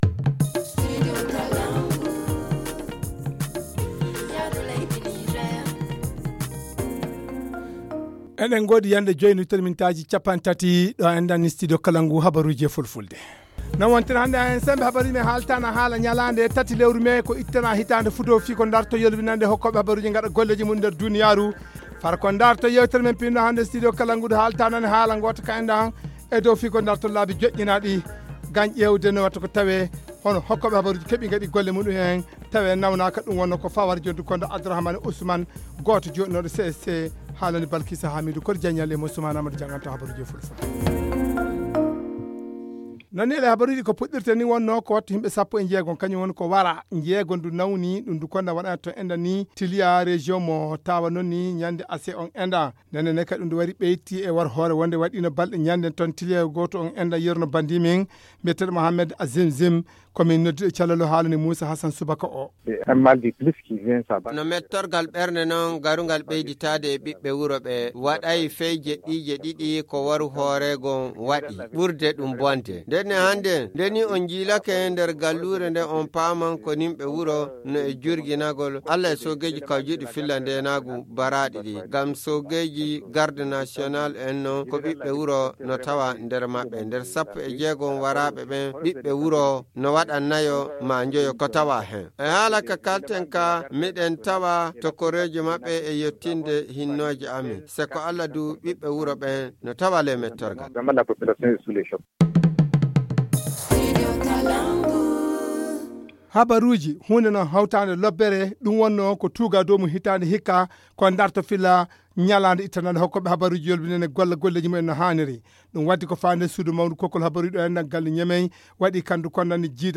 Le journal du 03 mai 2021 - Studio Kalangou - Au rythme du Niger